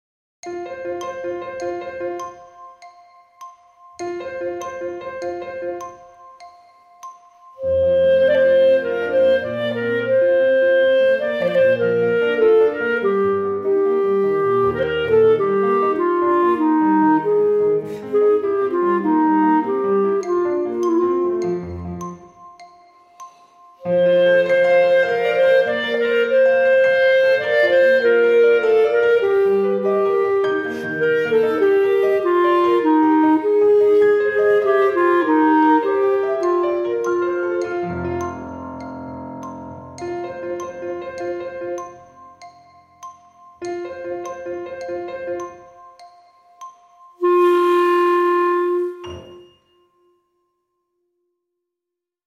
Audiokniha
• Čte: Kantiléna /Brno/